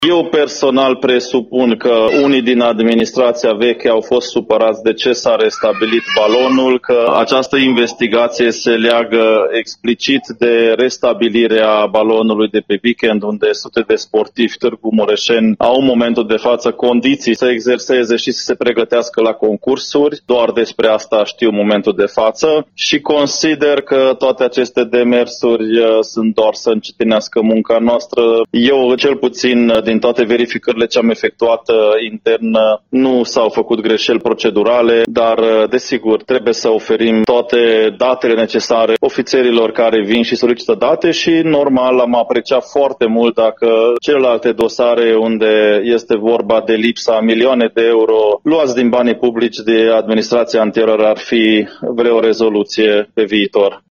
Procurorii au mai fost interesați și de investițiile pentru redeschiderea Balonului presostatic din Complexul de Sport și Agrement ”Mureșul”, mai spune primarul Soós Zoltán: